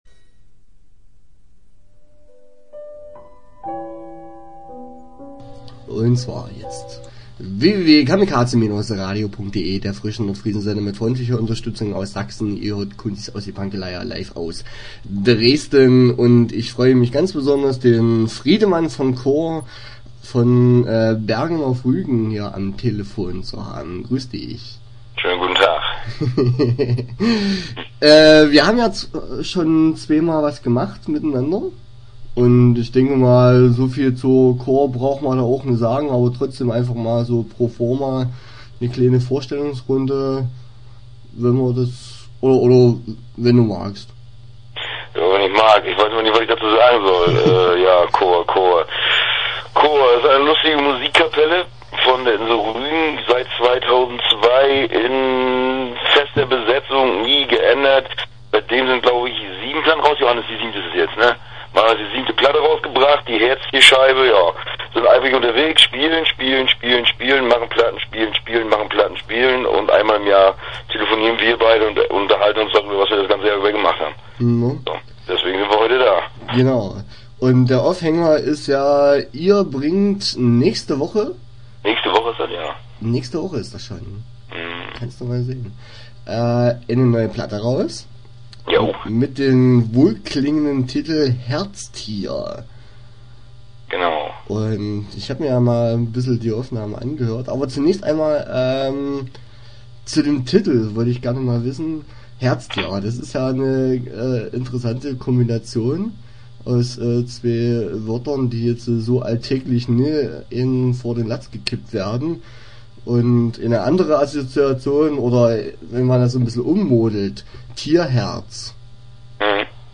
Interview Teil 1 (14:45)